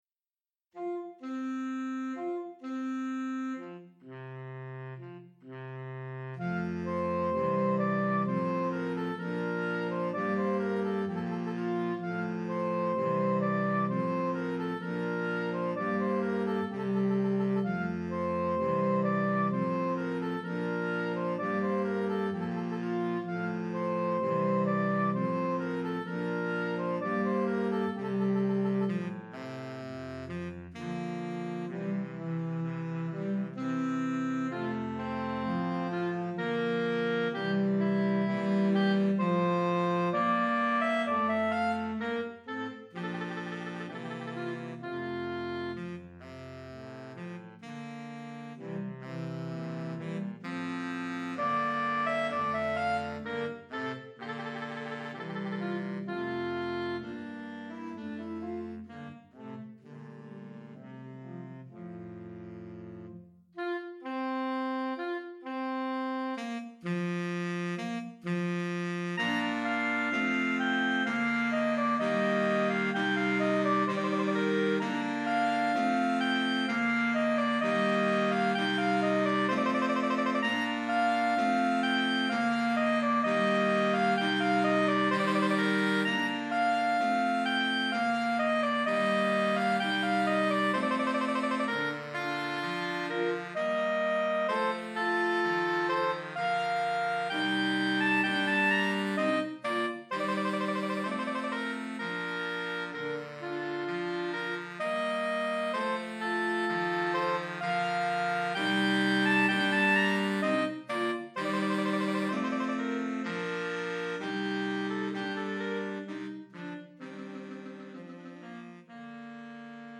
Saxophone Quartet
Instrumentation: Sop, Alto, Ten, Bart